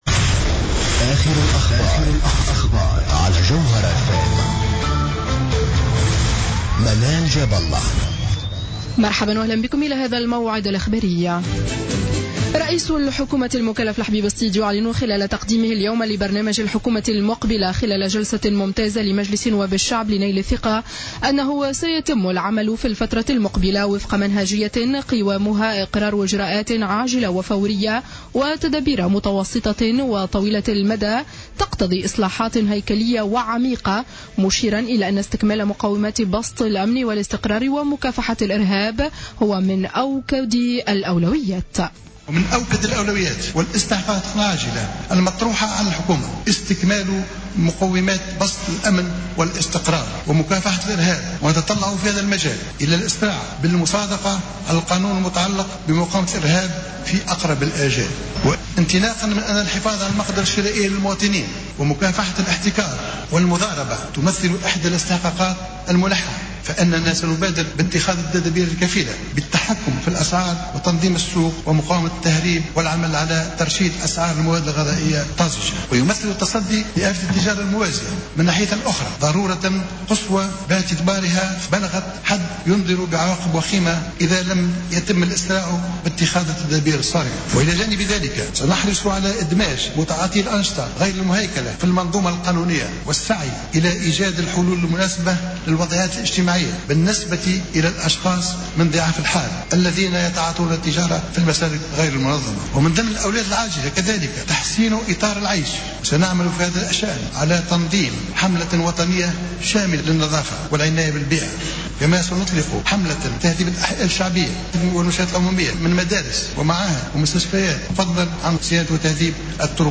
نشرة أخبار السابعة مساء ليوم الاربعاء 04-02-15